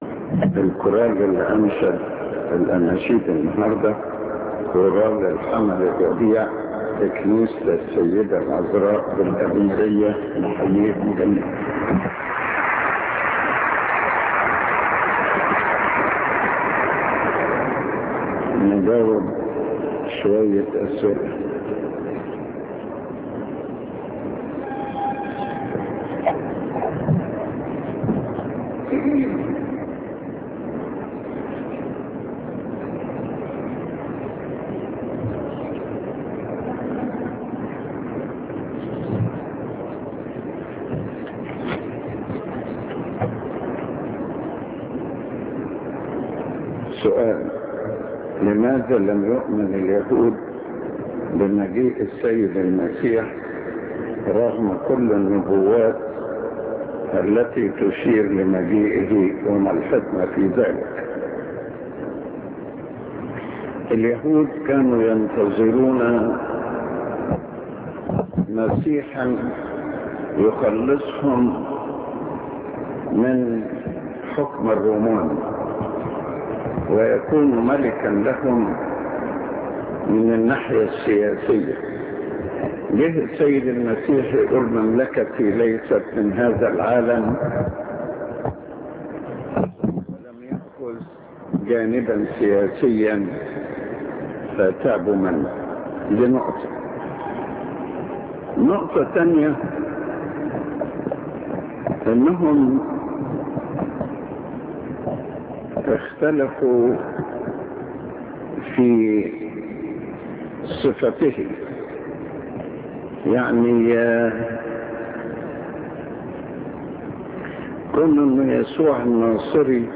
اخرعظة اسبوعية لقداسة البابا شنودة الثالث